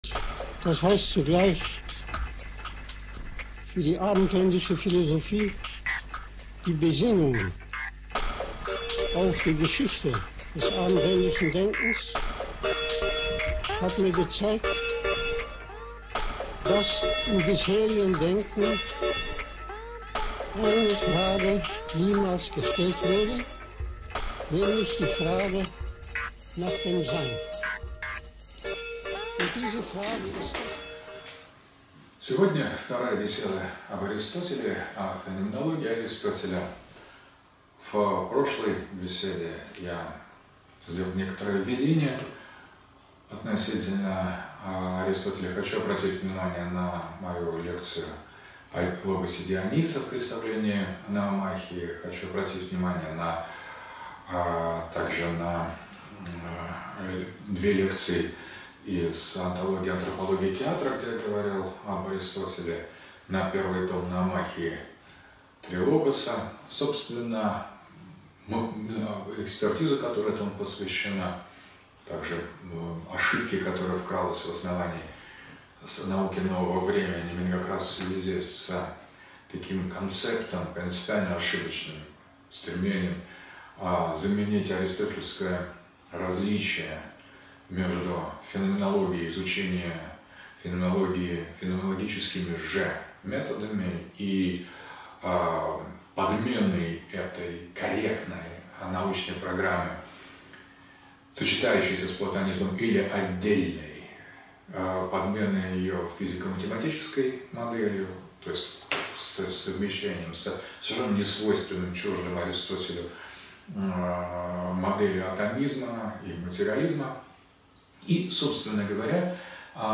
Феноменология Аристотеля. Лекция 2. Мойры и речи